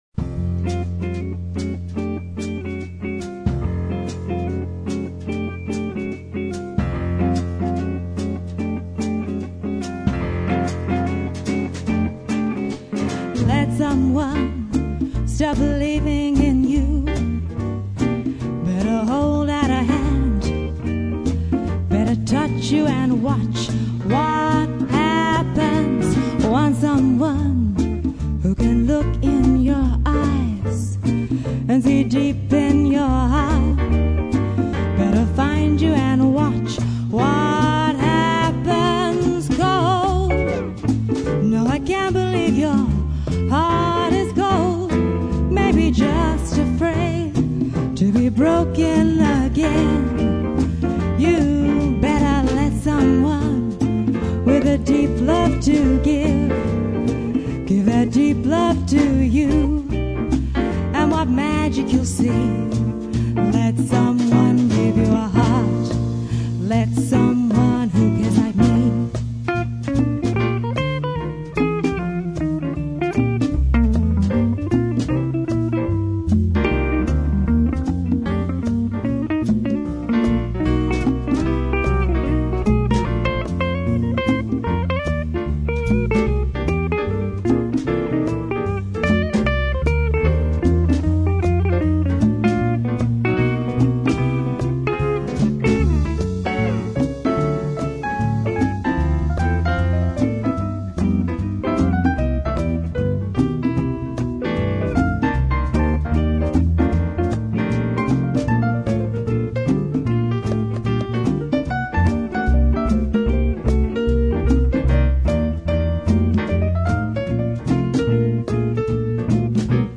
jazz/swing